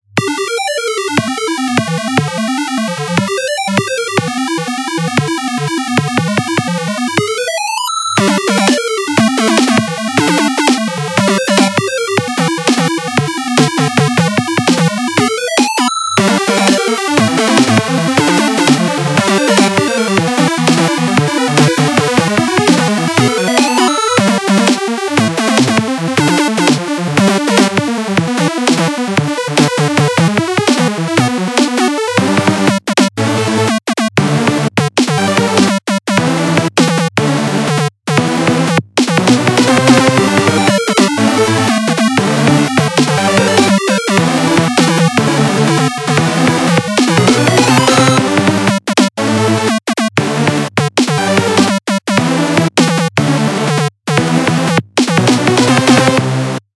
Set to 10 steps per second / measure